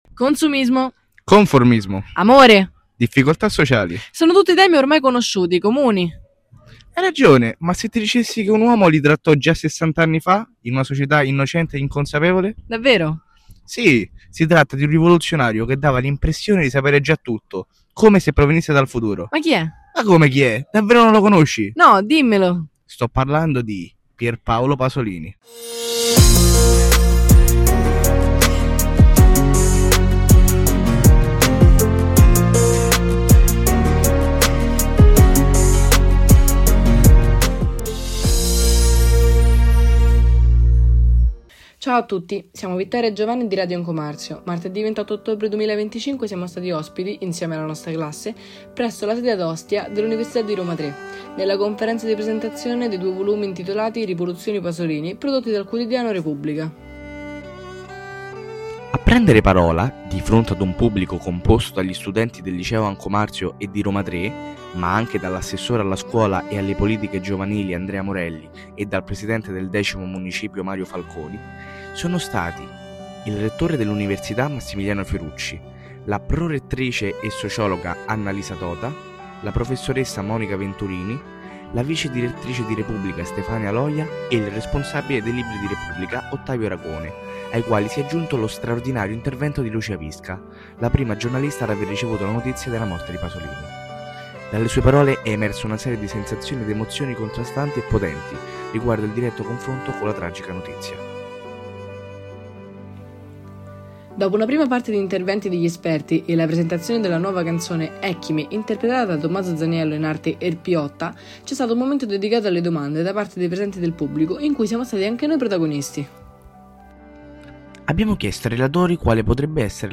Il podcast sulla conferenza di presentazione dei due volumi intitolati "Rivoluzione Pasolini" prodotti dal quotidiano Repubblica presso la sede di Ostia dell'Università di Roma Tre